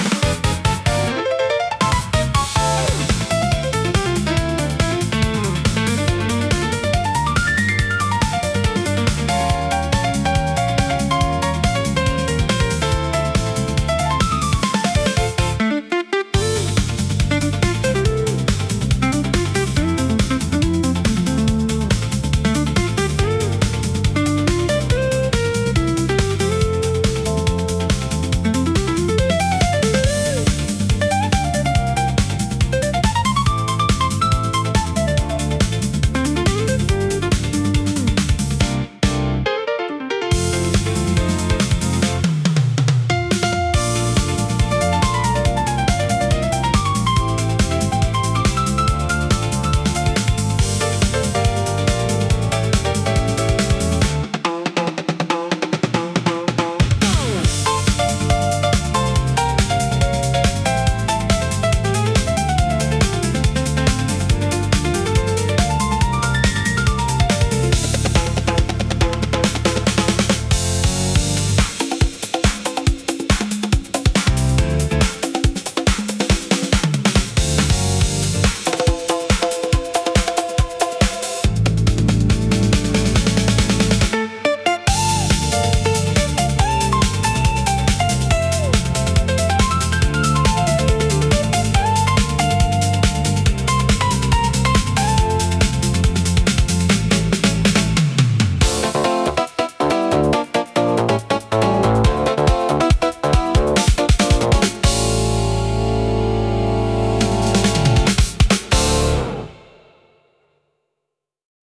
イメージ：インスト,シティーポップ,おしゃれ,爽やか
インストゥルメンタル（instrumental）